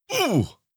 Damage Sounds
19. Damage Grunt (Male).wav